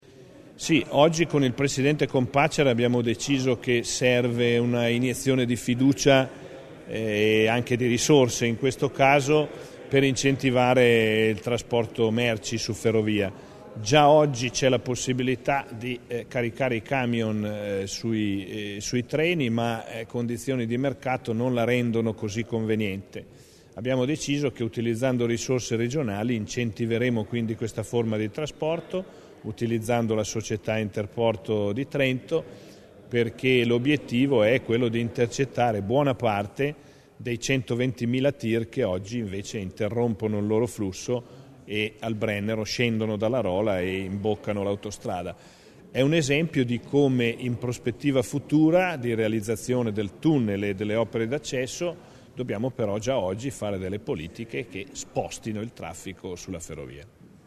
INTV_ROSSI_IT_64k.mp3